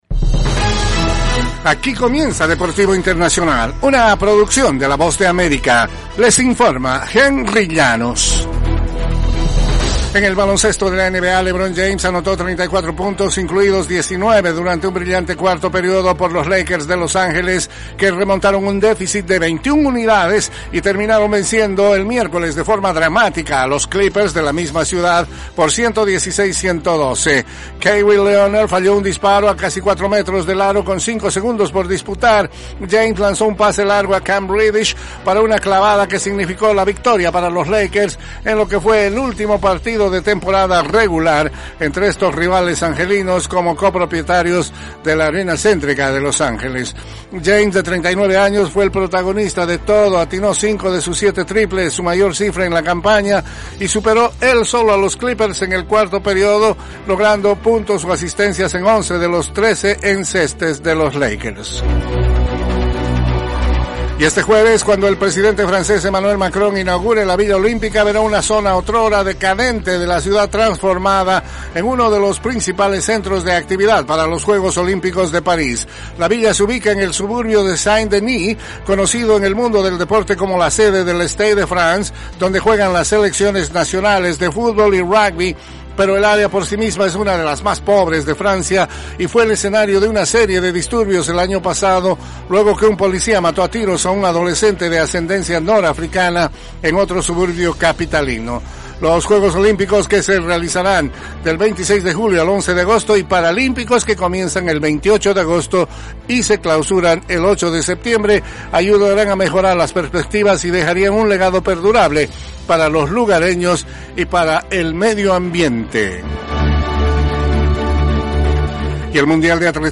Las noticias deportivas llegan desde los estudios de la Voz de América